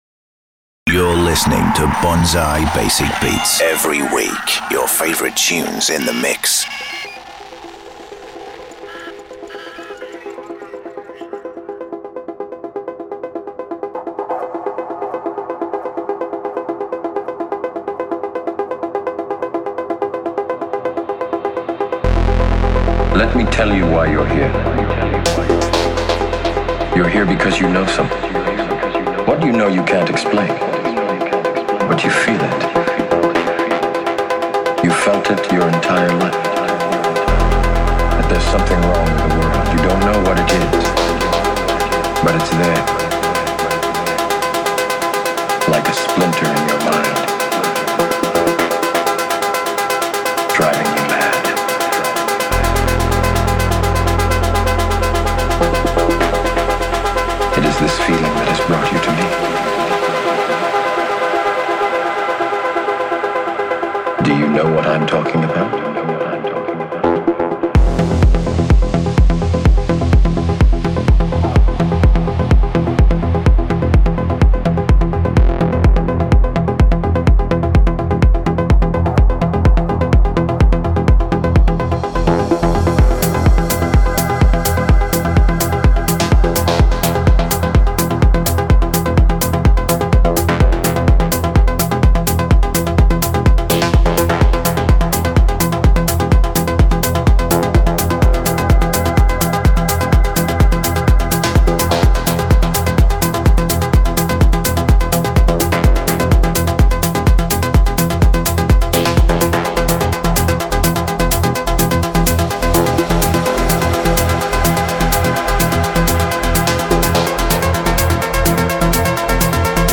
Also find other EDM Livesets, DJ Mixes and Radio Show
an outstanding selection of grooves